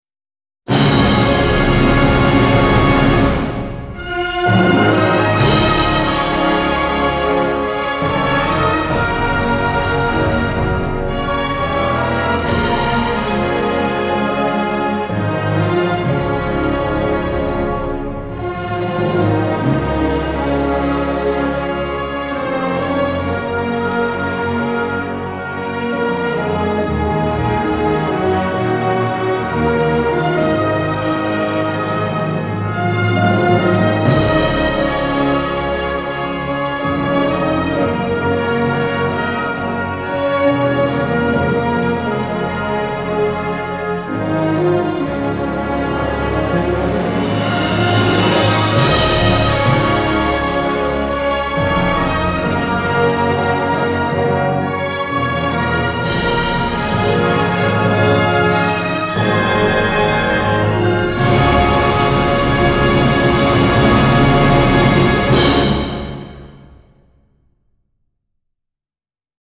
Оркестровая версия без слов Вариант 2